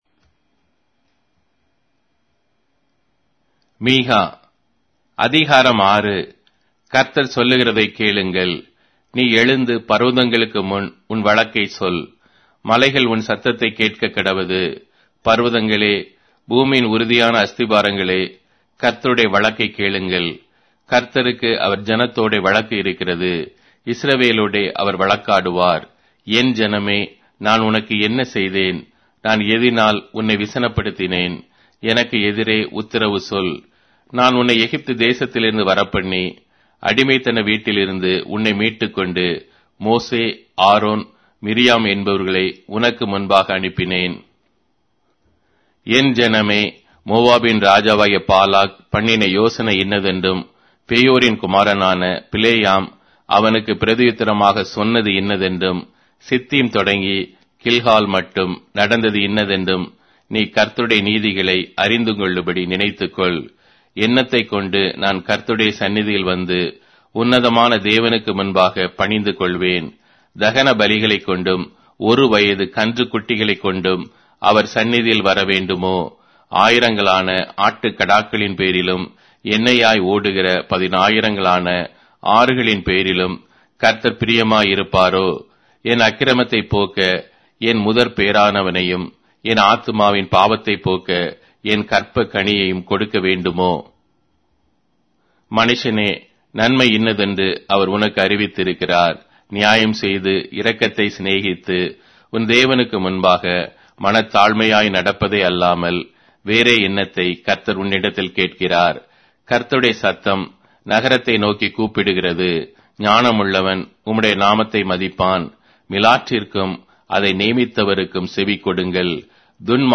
Tamil Audio Bible - Micah 7 in Ncv bible version